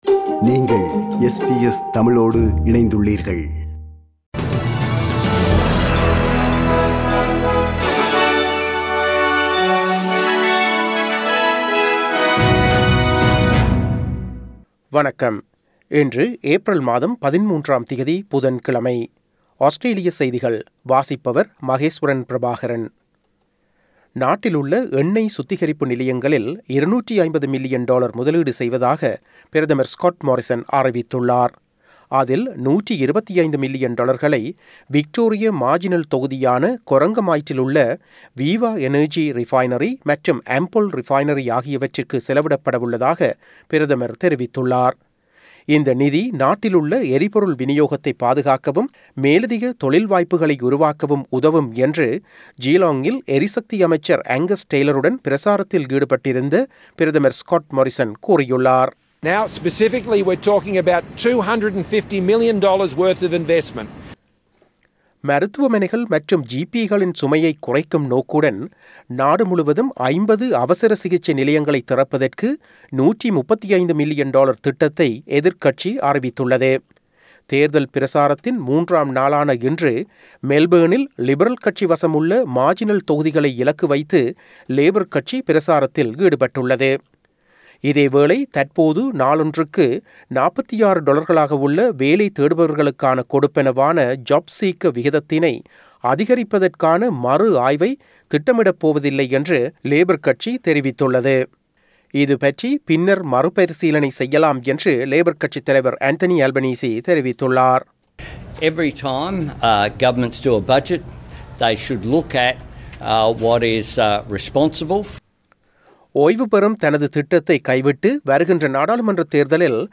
Australian news bulletin for Wednesday 13 April 2022.